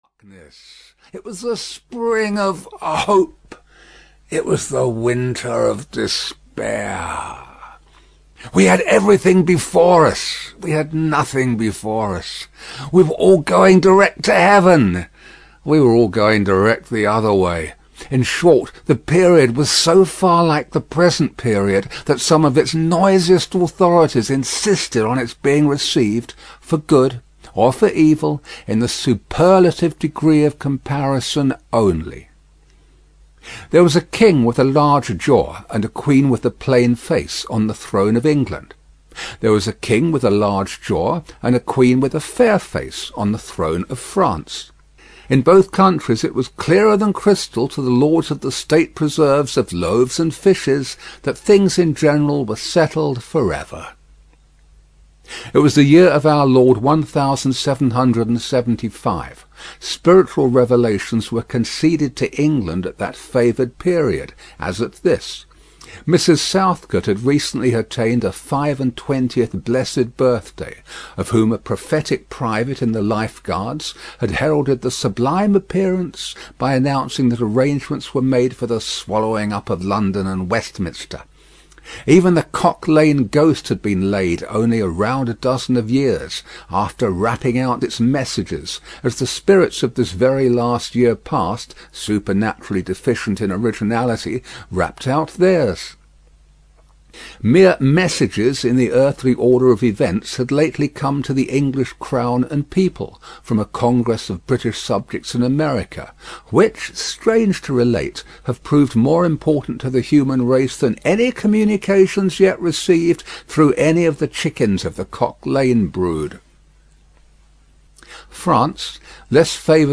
A Tale of Two Cities (EN) audiokniha
Ukázka z knihy